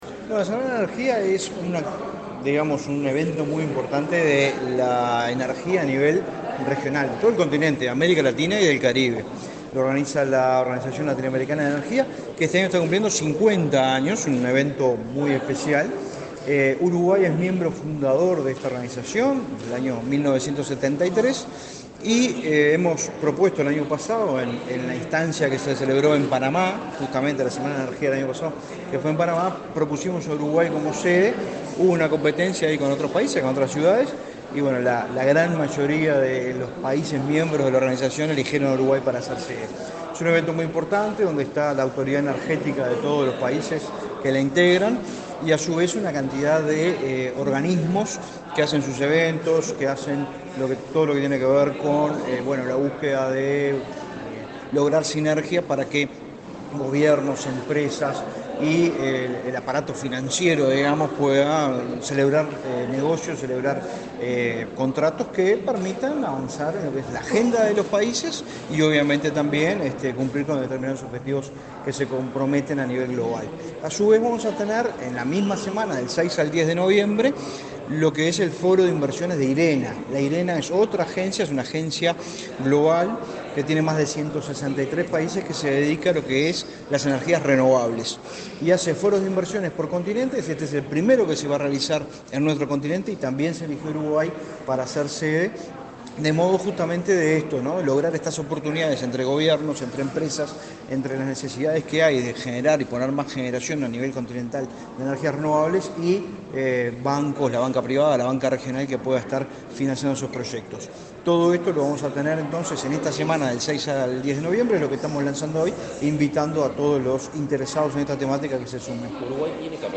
Declaraciones del director nacional de Energía, Fitzgerald Cantero
Declaraciones del director nacional de Energía, Fitzgerald Cantero 17/08/2023 Compartir Facebook X Copiar enlace WhatsApp LinkedIn Este jueves 17 en la Torre Ejecutiva, el director nacional de Energía, Fitzgerald Cantero, dialogó con la prensa, antes de participar en el lanzamiento de la VIII Semana de la Energía y el Foro de Inversión de Transición Energética de América Latina.